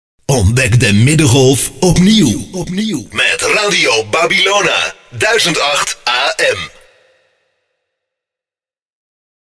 Onze Jingles / Unser Jingles